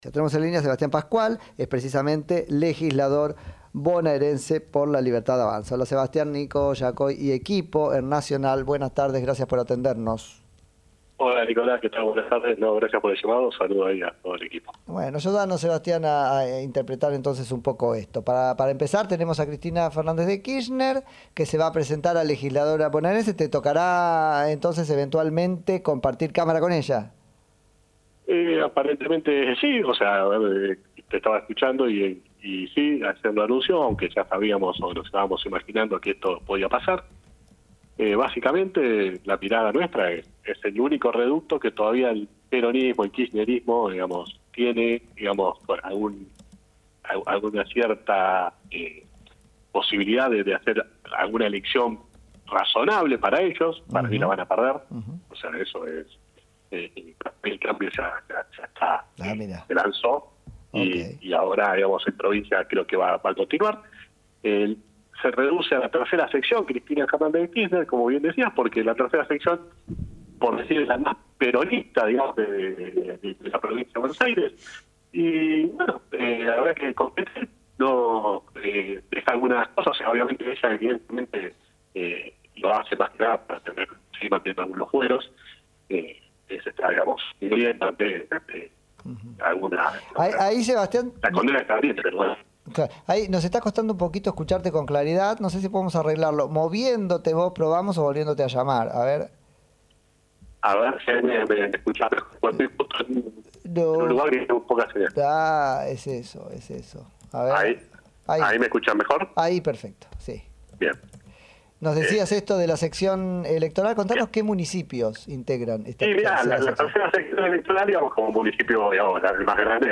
emotivo acto Cristina Fernández se despidió de los trabajadores del Senado